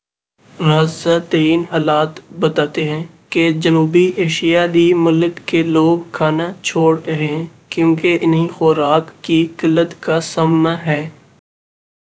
deepfake_detection_dataset_urdu / Spoofed_TTS /Speaker_15 /271.wav